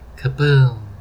snd_boss_destroy.wav